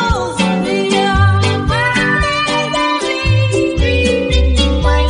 When_the_Saints_violin_i2i_vocals.wav